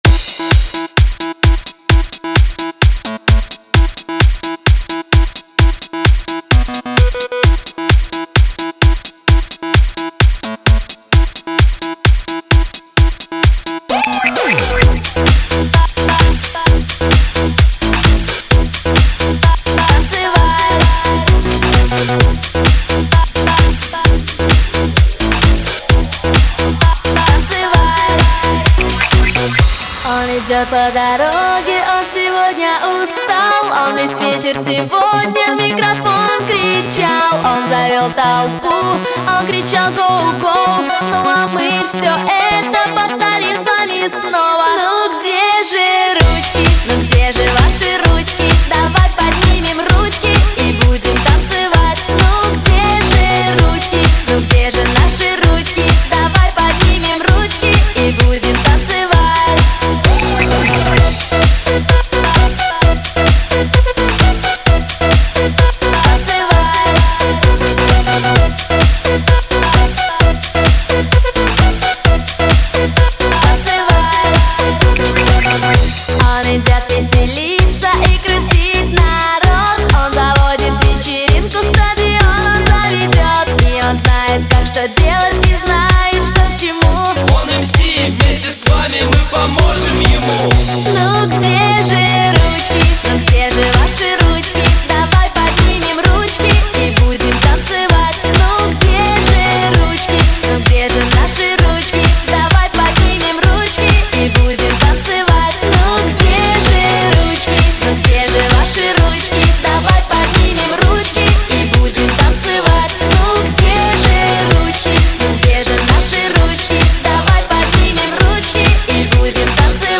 Категория: Попсовые песни